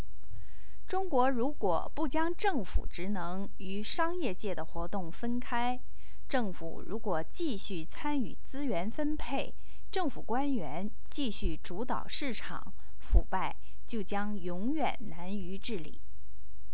Two anthropologists, one Chinese, the other British,  are talking about corruption in China.